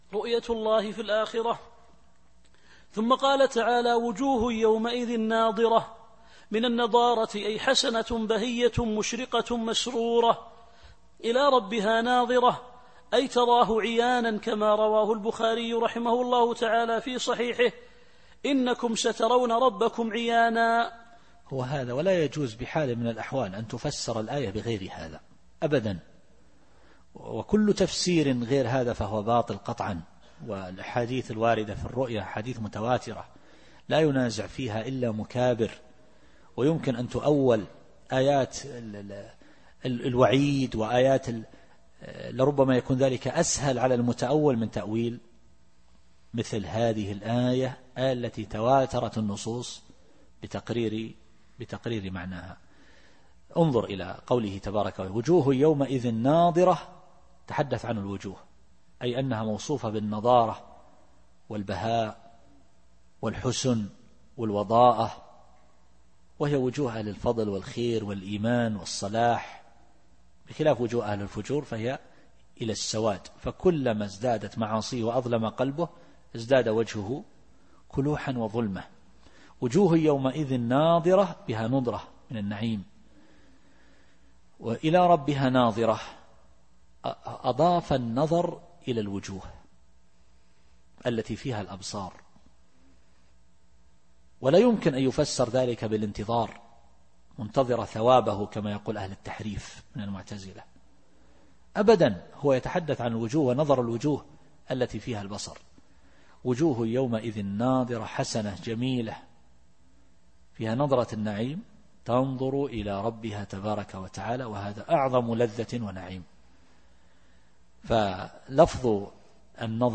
التفسير الصوتي [القيامة / 23]